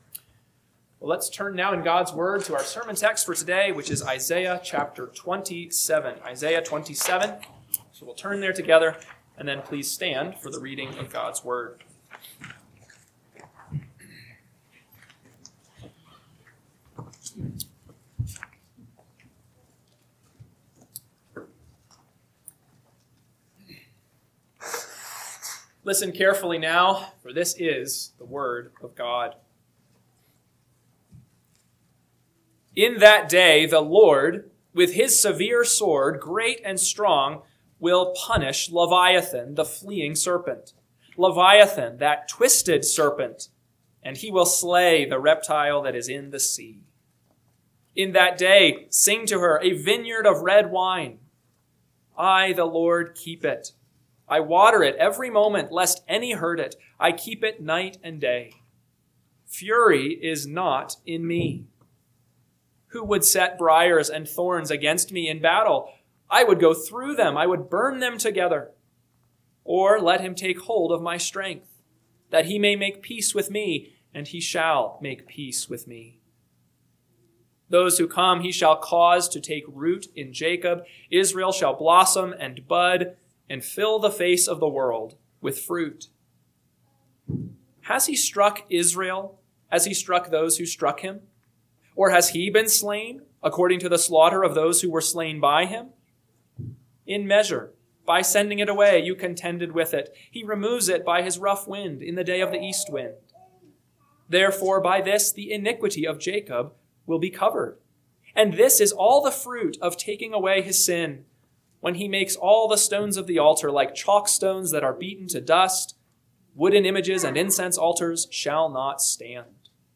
AM Sermon – 4/19/2026 – Isaiah 27 – Northwoods Sermons